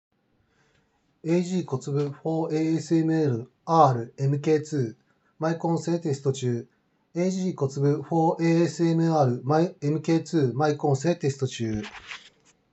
よくも悪くもない平均的なマイク性能
少しこもっているが普通に聞ける性能があります。
✅「ag COTSUBU for ASMR MK2」マイク性能